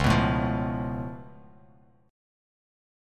C#+7 chord